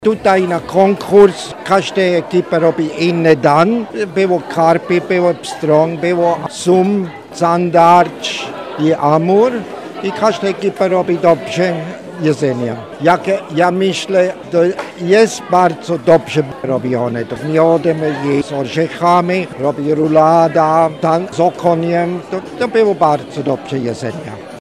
– mówi Kurt Scheller, szwajcarski krytyk kulinarny.